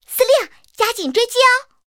SU-26夜战语音.OGG